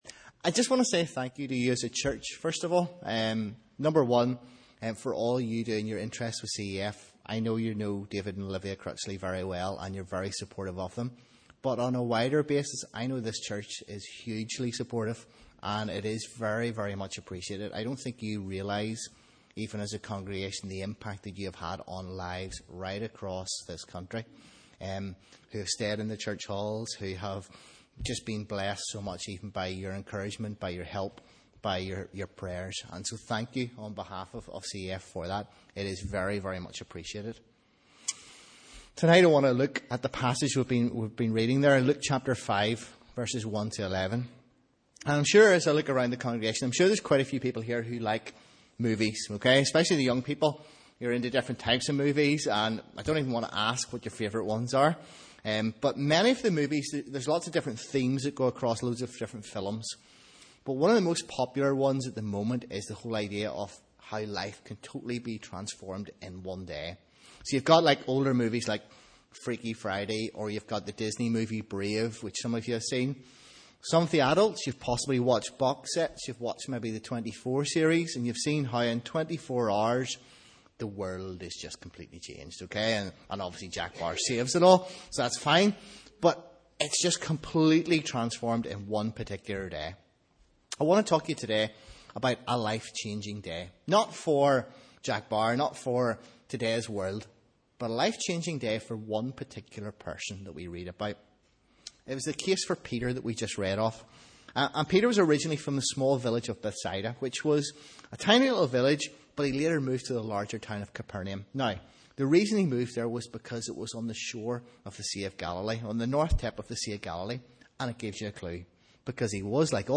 Sunday School Celebration Service – Evening Service